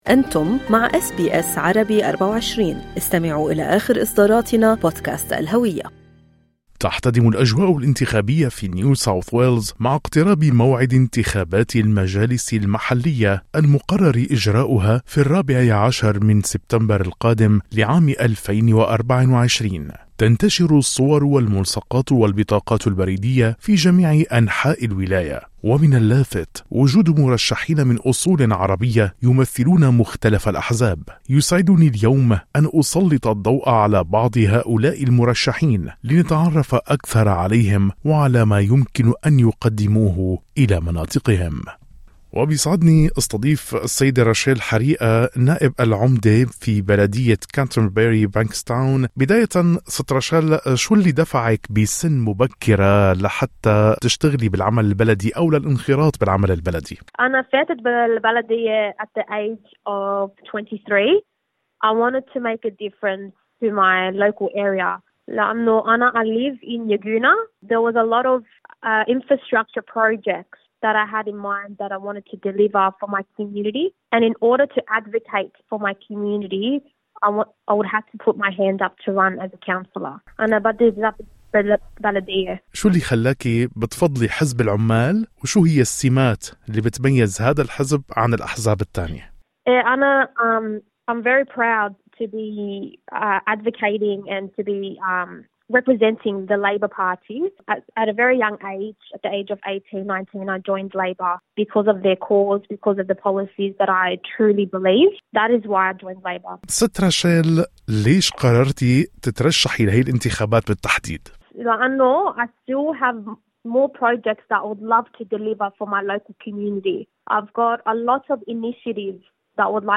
الانتخابات المحلية في نيو ساوث ويلز: حوار مع مرشحين من خلفيات عربية